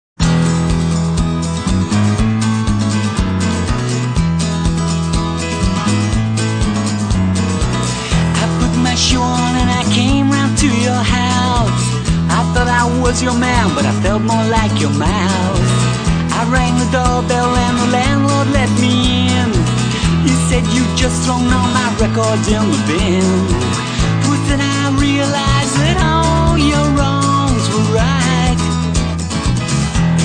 quirky idiosyncratic numbers